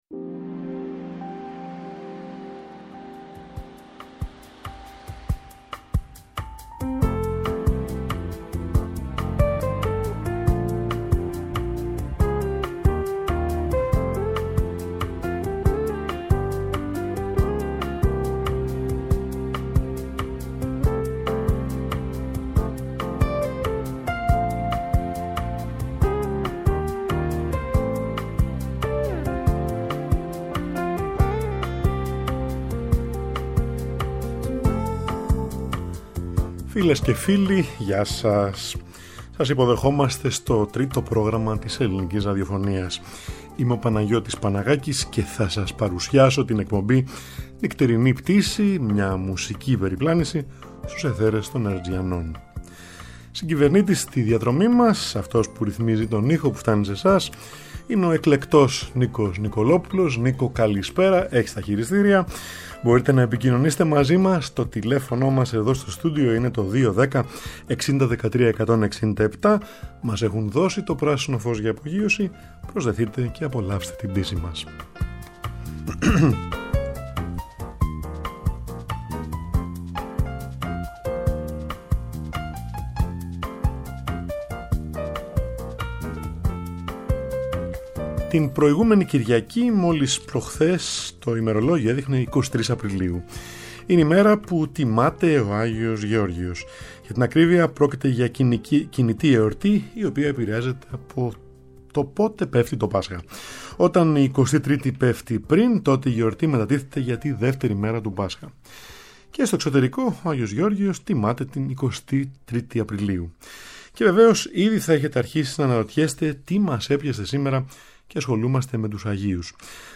Θα ακούσουμε αυτούς τους μέγιστους βιολονίστες
κοντσέρτα